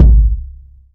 Kicks
KICK.79.NEPT.wav